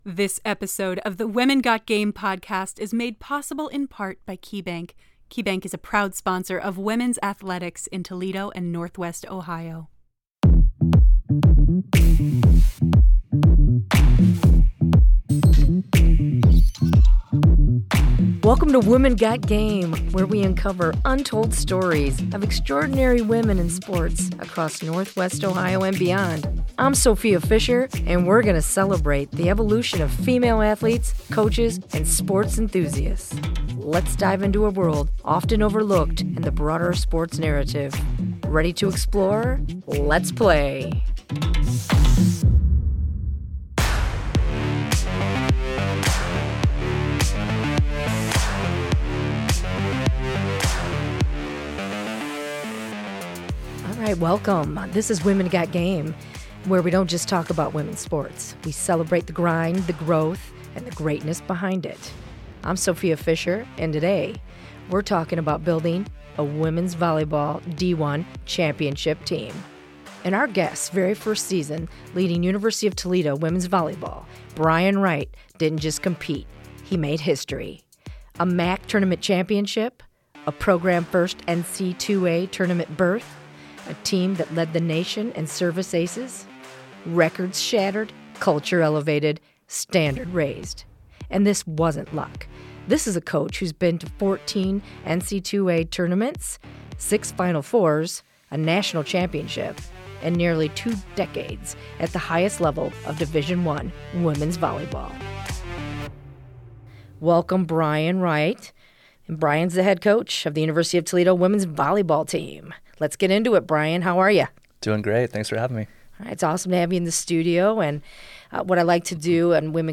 /*-->*/ /*-->*/ In this episode, we dive into the journey from Big Ten experience to winning a MAC title, exploring leadership, culture, resilience, and what it means to coach and empower female athletes today. From NIL and personal branding to mental toughness and team dynamics, this conversation goes beyond the game.